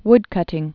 (wdkŭtĭng)